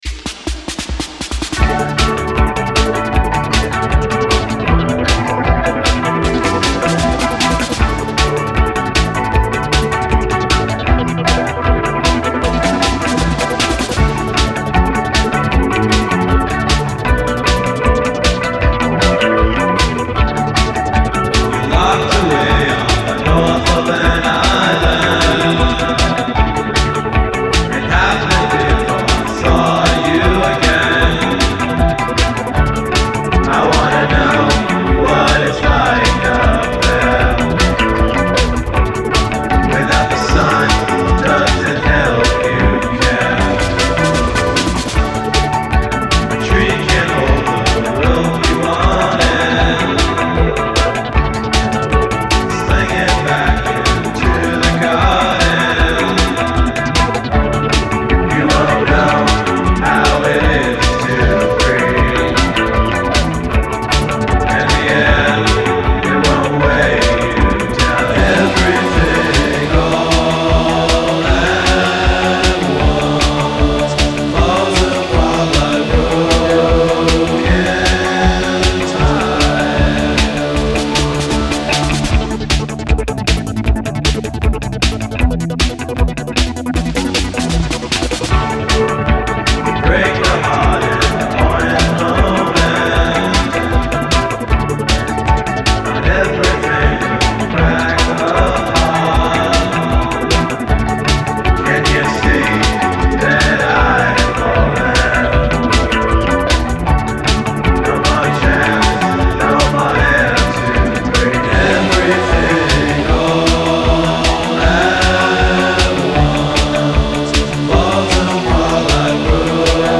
Indie Rock, MP3, Post-Punk